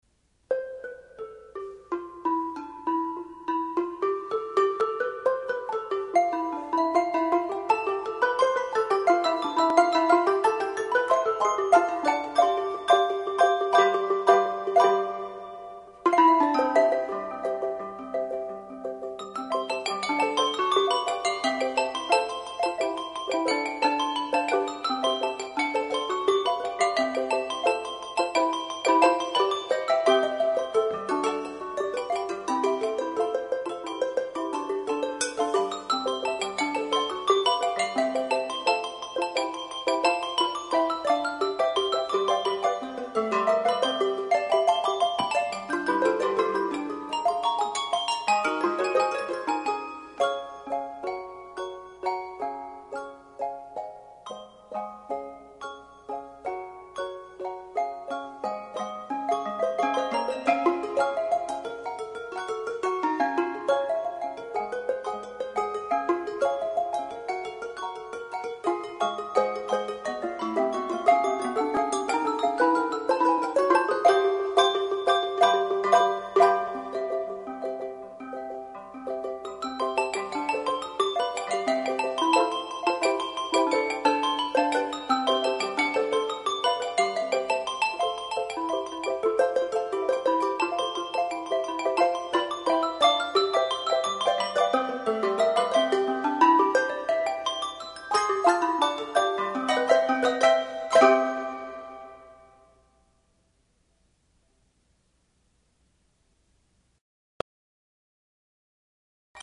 Handbell Quartet
No. Octaves 3 Octaves